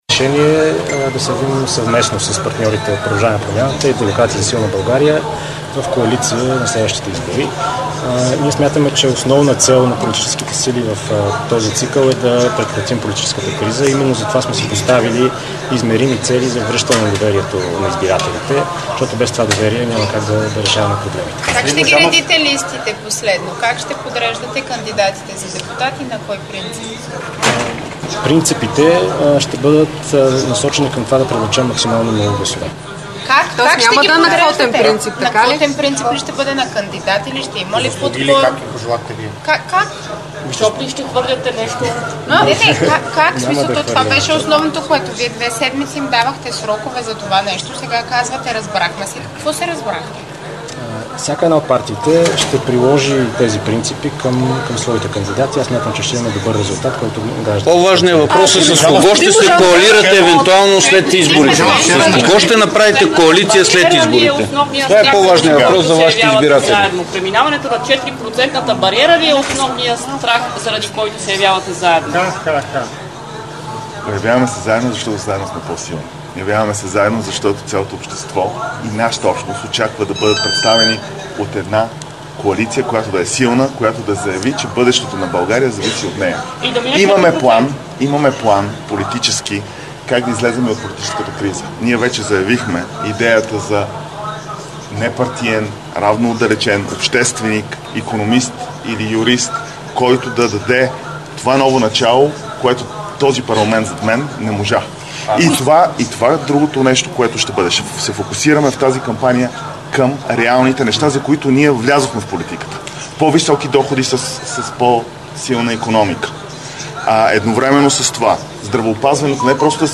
9.25 - Брифинг на съпредседателя на ДПС Делян Пеевски.  - директно от мястото на събитието (Народното събрание)
Директно от мястото на събитието